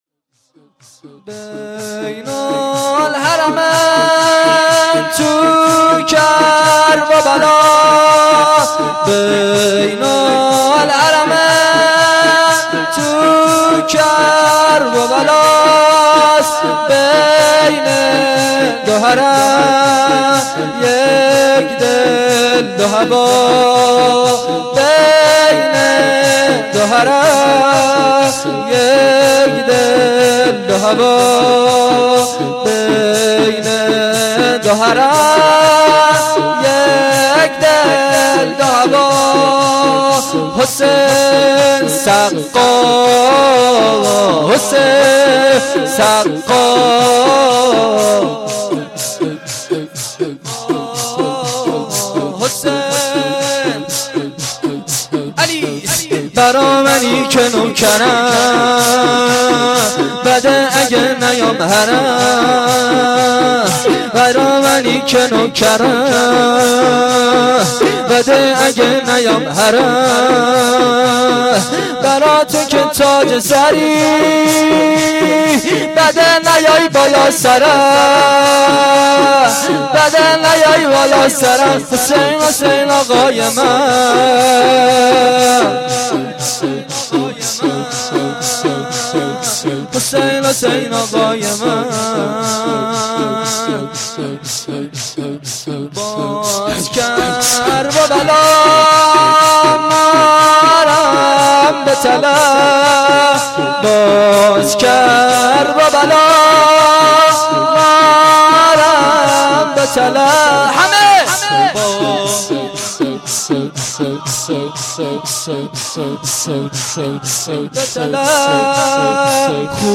گزارش صوتی جلسه رحلت امام